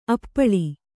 ♪ appaḷi